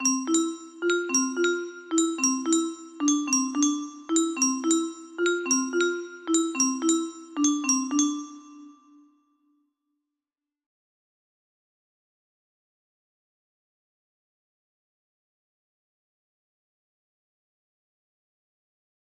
music box melody
Lil beat I made